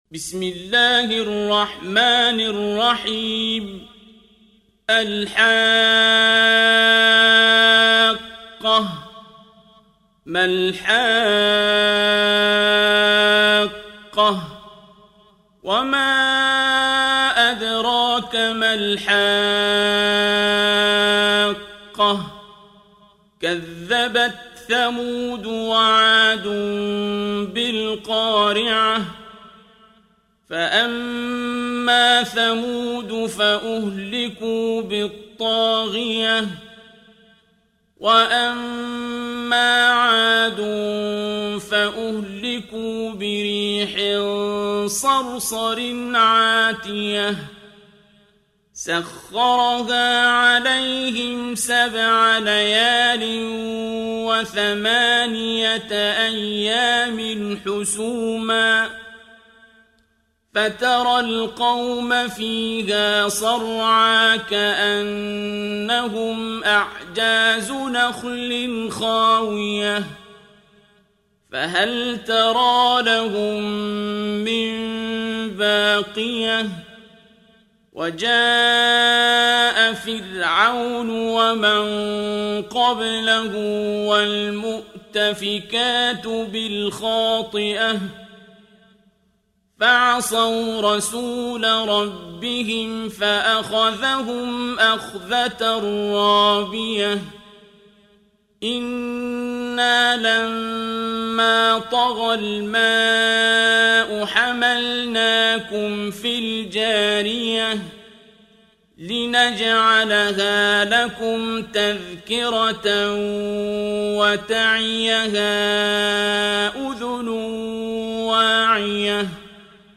Коран mp3 - сборник чтений Священного Корана - 'Абдуль-Басит 'Абдуль-Самад * – القارئ عبد الباسط عبد الصمد